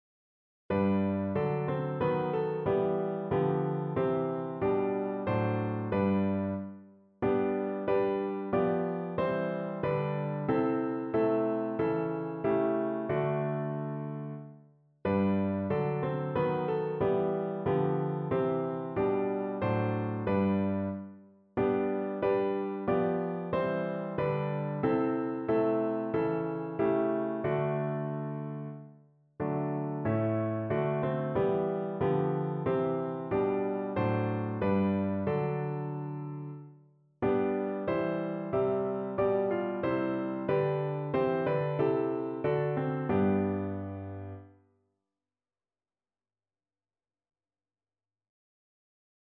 Notensatz 1 (4 Stimmen gemischt)
• gemischter Chor [MP3] 769 KB Download